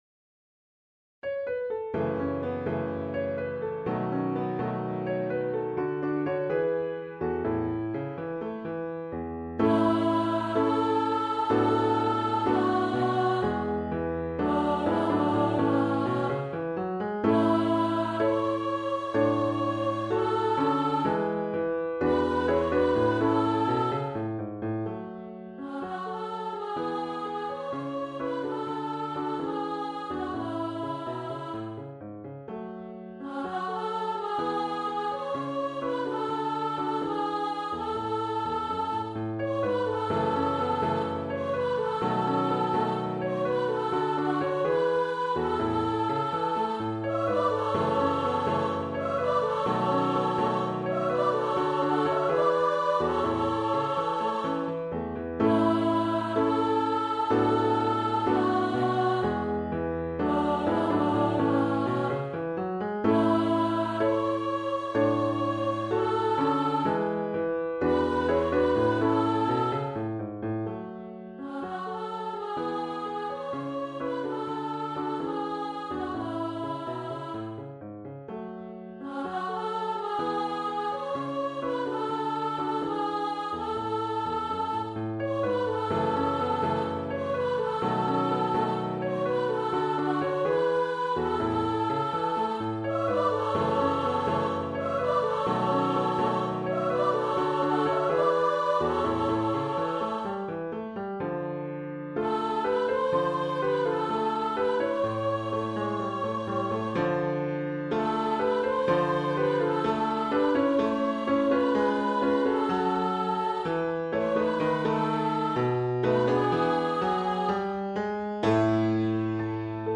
Lleida_amb_melodia.mp3